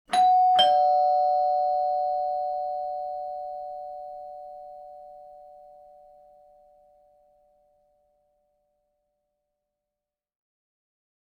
دانلود آهنگ زنگ در 1 از افکت صوتی اشیاء
دانلود صدای زنگ در 1 از ساعد نیوز با لینک مستقیم و کیفیت بالا
جلوه های صوتی